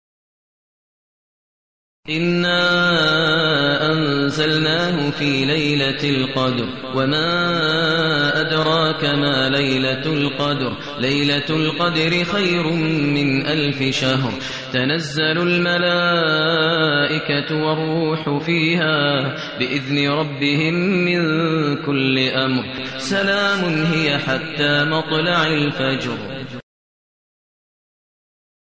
المكان: المسجد النبوي الشيخ: فضيلة الشيخ ماهر المعيقلي فضيلة الشيخ ماهر المعيقلي القدر